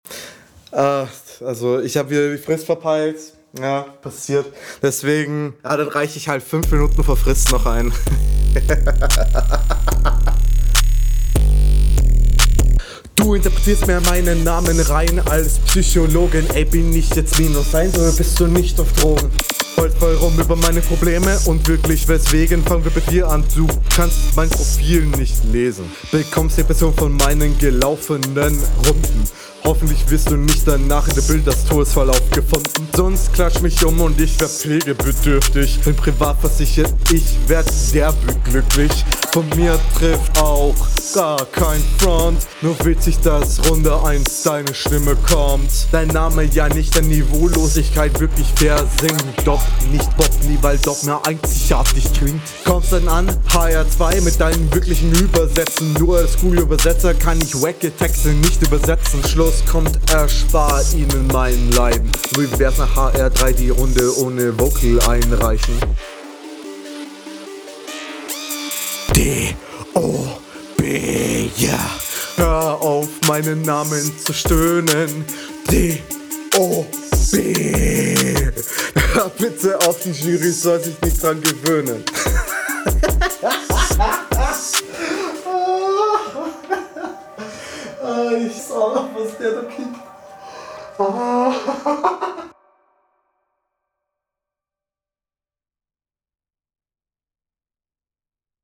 Flow: Stimmfarbe und melodische Variationen gefallen mir gut, allerdings ist der Flow relativ holprig und …
Flow: flow ist ganz solide aber finde das da ein paar flow fehler und die …